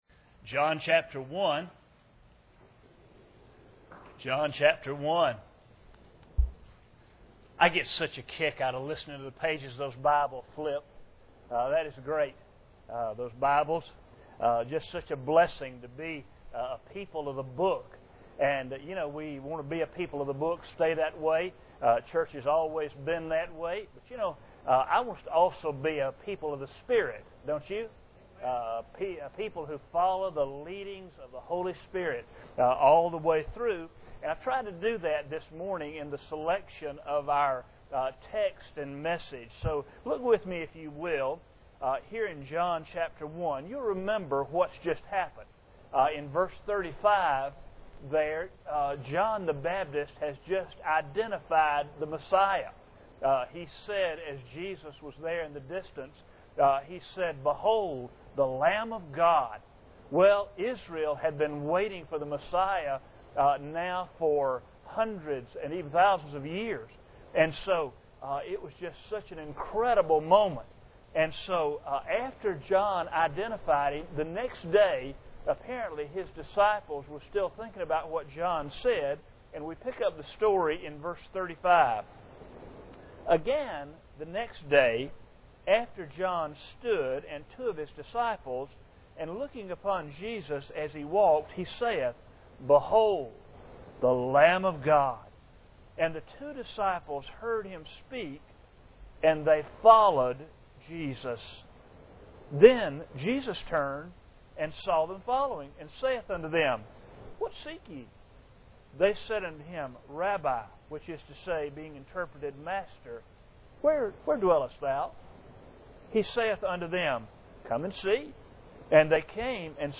John 1:35-42 Service Type: Sunday Morning Bible Text